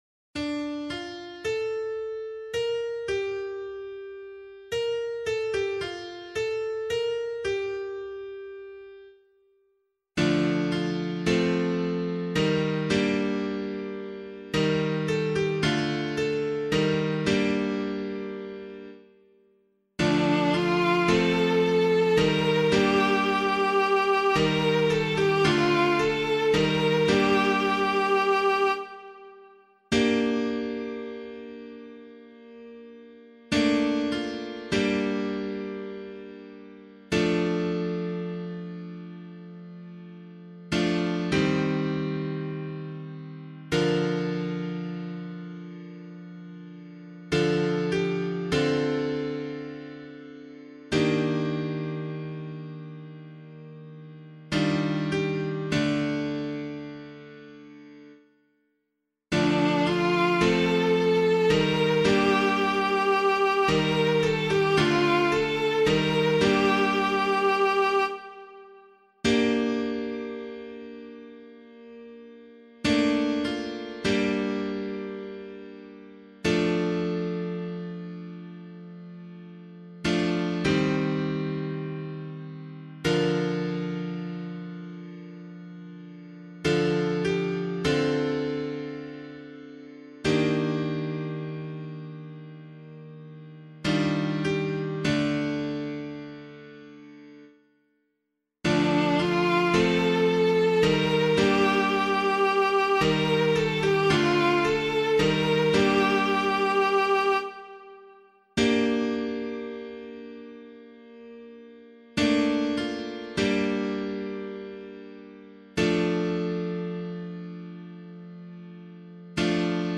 004 Advent 4 Psalm A [APC - LiturgyShare + Meinrad 4] - piano.mp3